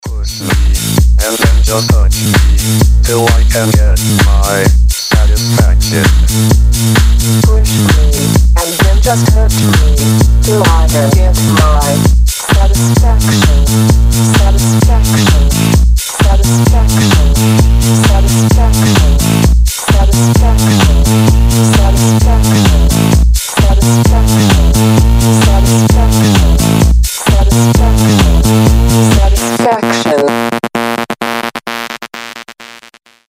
• Качество: 320, Stereo
мужской голос
ритмичные
женский голос
Electronic
чувственные
electro house
электронный голос